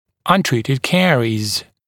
[ʌn’triːtɪd ‘keəriːz][ан’три:тид ‘кэари:з]нелеченый кариес